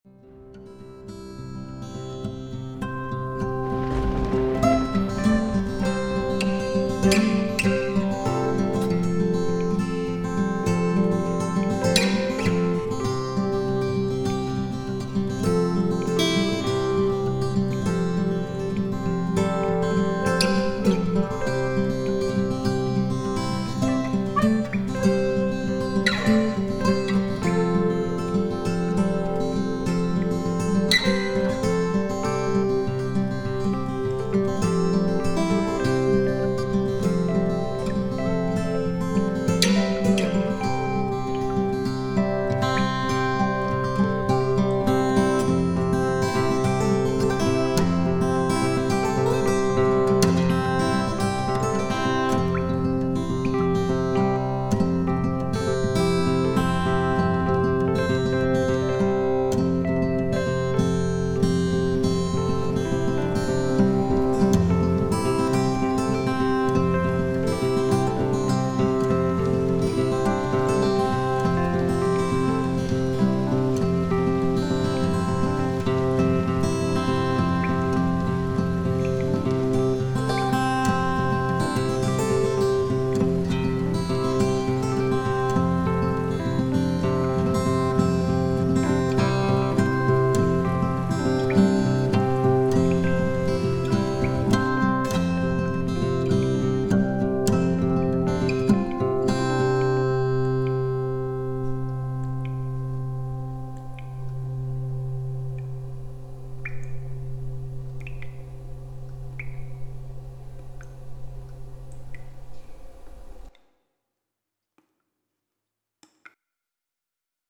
The recordings are all quite amateur, and most are, what I would refer to as, sketches, as opposed to finished and refined pieces of music.
Most are instrumental guitar, some are banjo and a few other instruments that I play with enough skill to record with them.